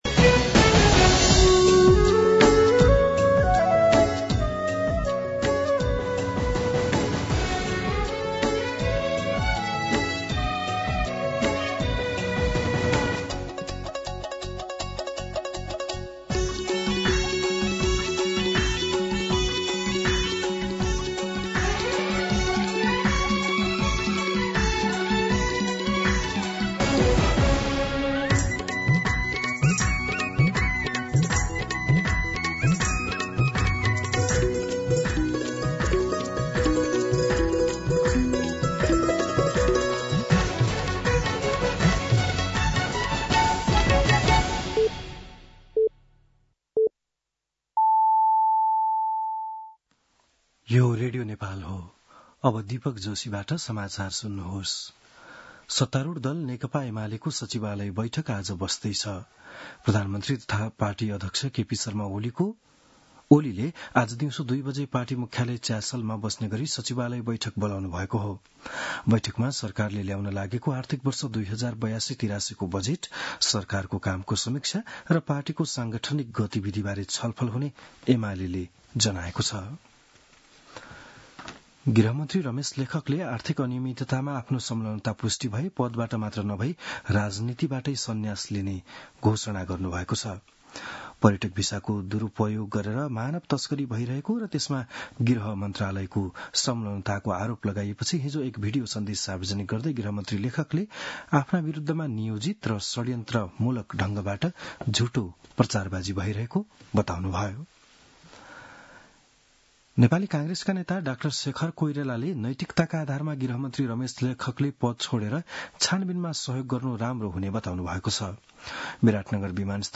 बिहान ११ बजेको नेपाली समाचार : ११ जेठ , २०८२